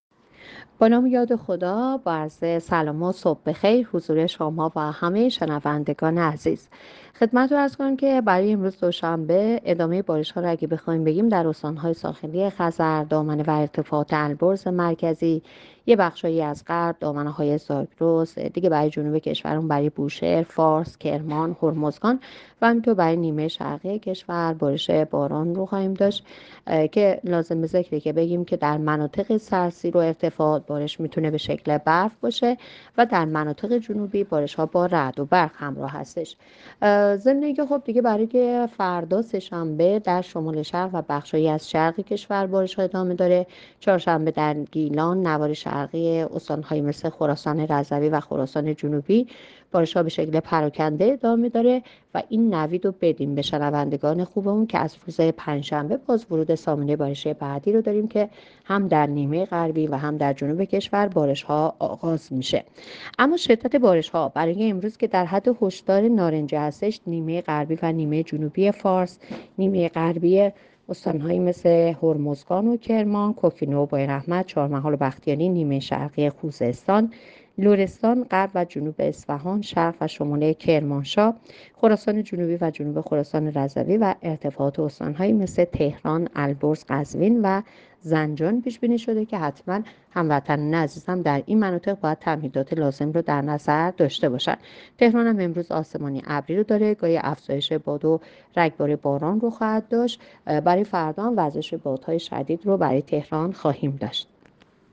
گزارش رادیو اینترنتی پایگاه‌ خبری از آخرین وضعیت آب‌وهوای ۲۹ بهمن؛